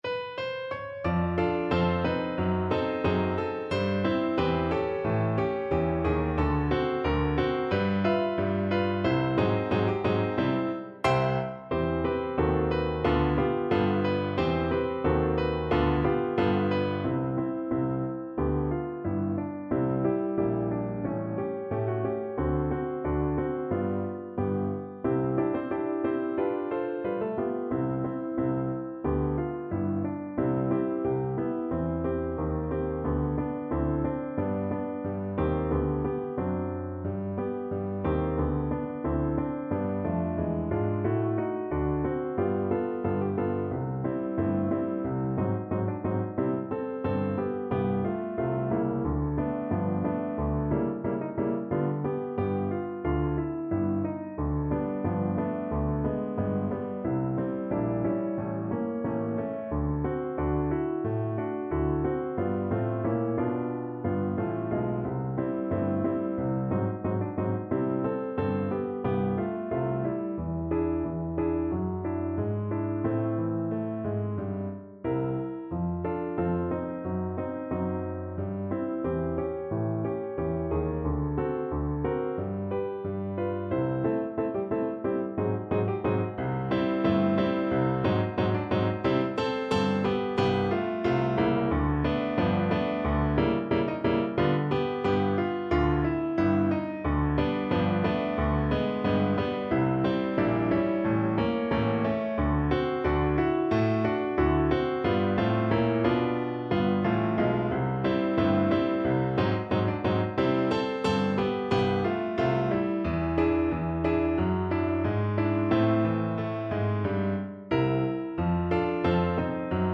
Flute version
2/2 (View more 2/2 Music)
~ = 180 Moderato
Pop (View more Pop Flute Music)